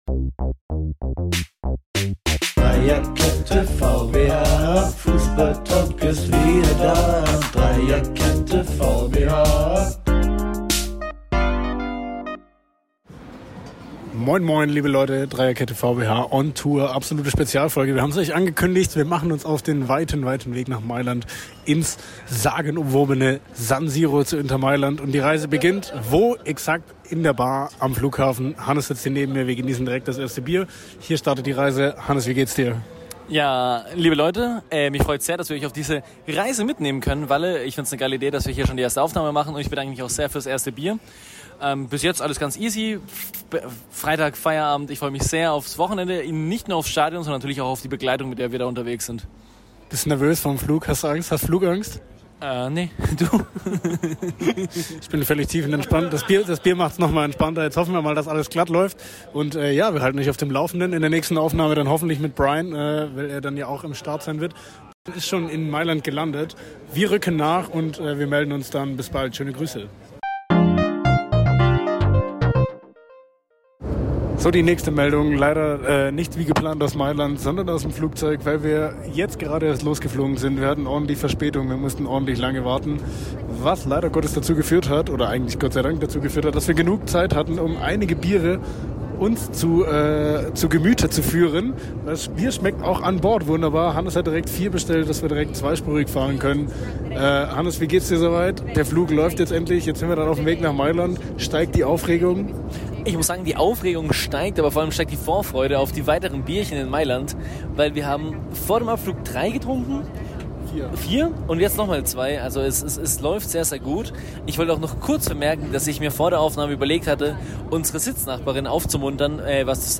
#66 VBH on Tour live aus Mailand ~ Dreierkette VBH Podcast